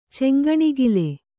♪ ceŋgaṇigile